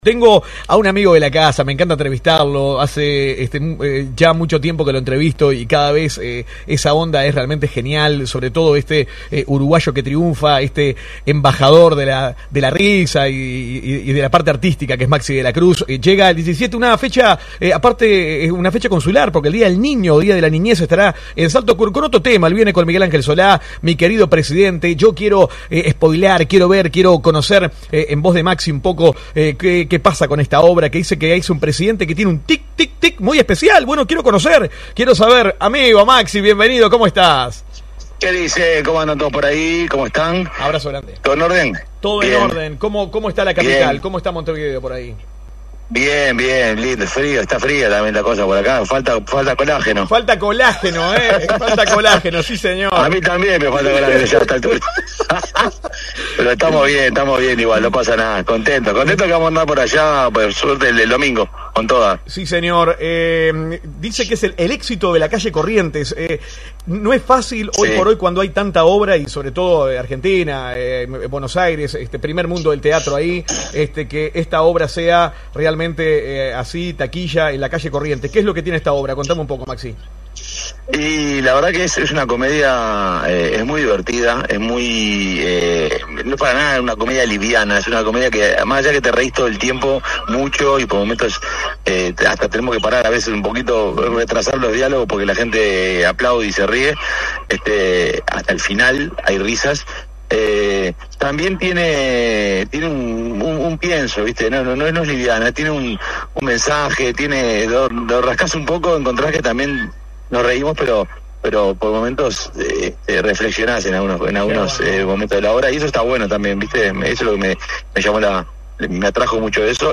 Entrevista telefónica con Maxi de la Cruz hablando de la obra que Co-protagoniza con Miguel Angel Solá – TEMPLARIA PLUS ON LINE RADIO